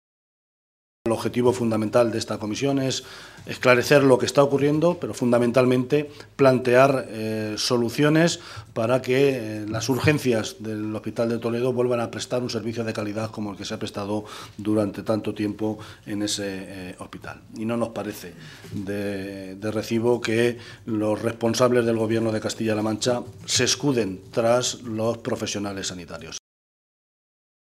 José Luis Martínez Guijarro, en rueda de prensa
Cortes de audio de la rueda de prensa